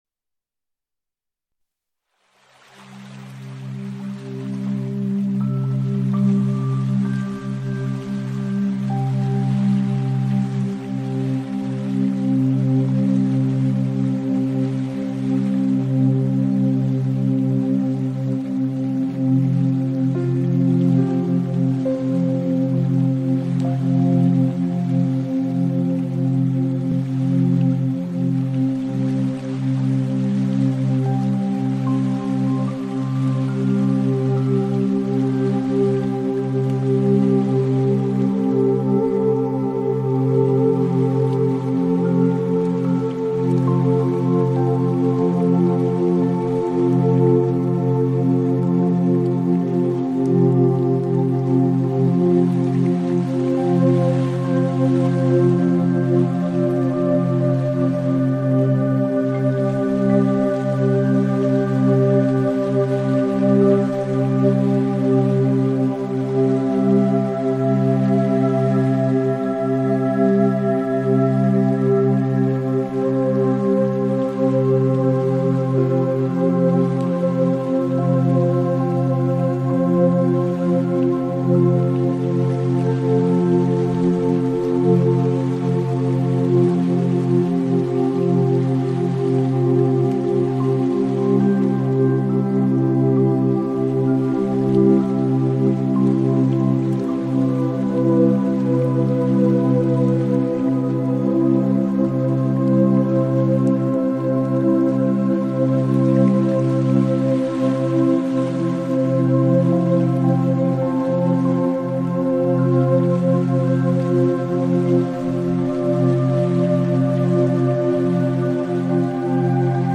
Música de relajación